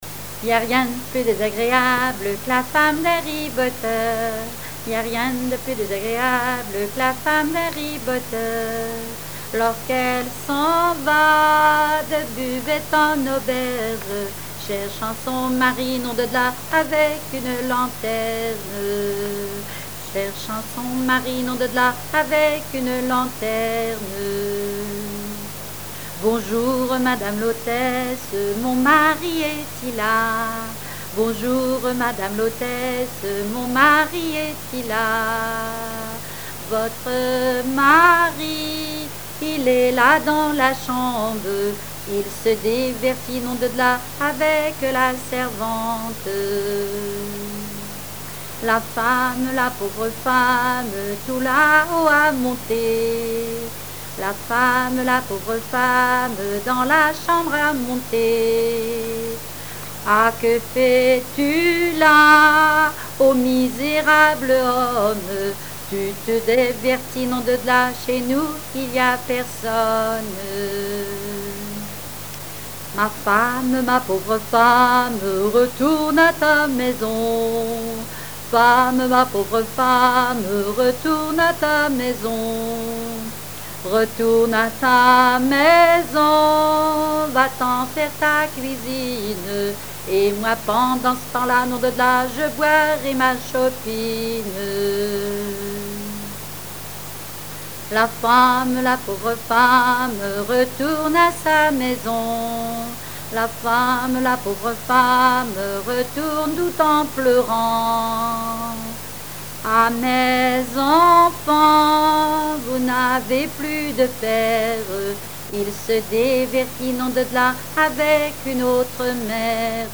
Genre strophique
répertoire de chansons populaire et traditionnelles
Pièce musicale inédite